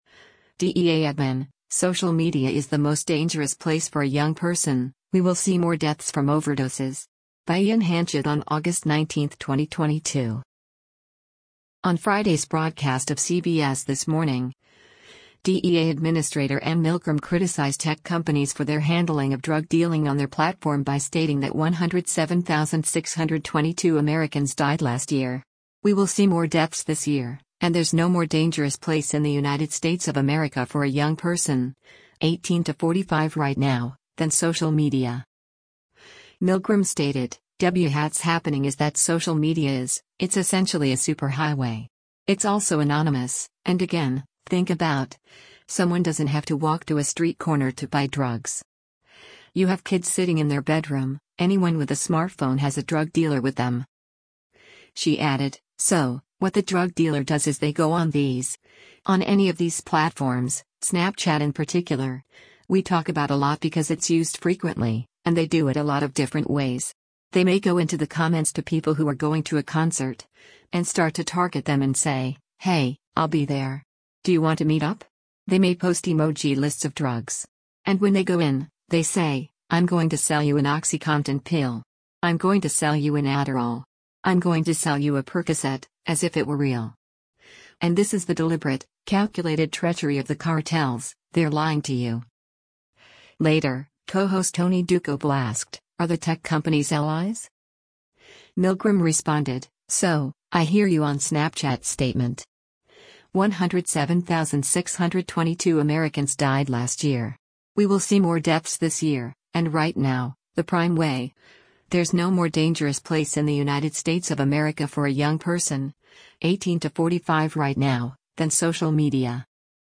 On Friday’s broadcast of “CBS This Morning,” DEA Administrator Anne Milgram criticized tech companies for their handling of drug dealing on their platform by stating that “107,622 Americans died last year. We will see more deaths this year,” and “there’s no more dangerous place in the United States of America for a young person, 18-45 right now, than social media.”
Later, co-host Tony Dokoupil asked, “Are the tech companies allies?”